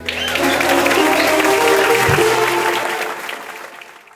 fanfare-2.ogg